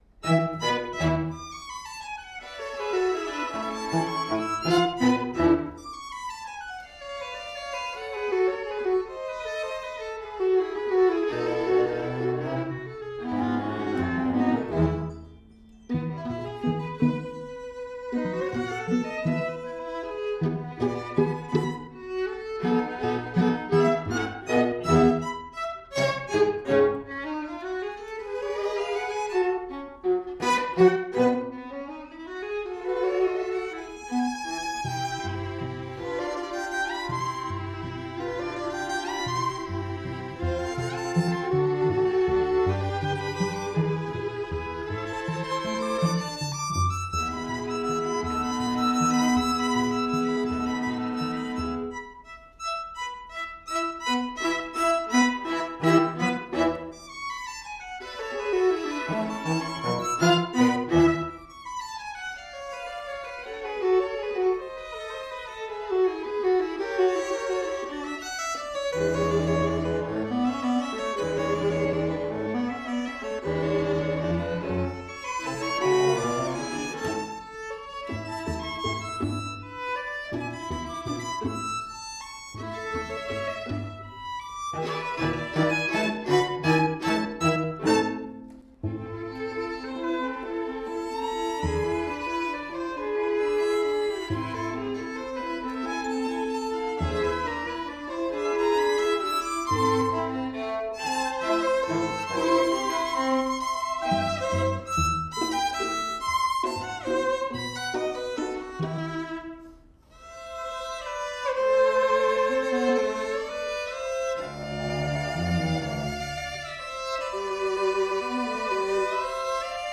Soundbite 2nd Movt
For 2 Violins, 2 Violas and Cello